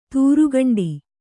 ♪ tūrugaṇḍi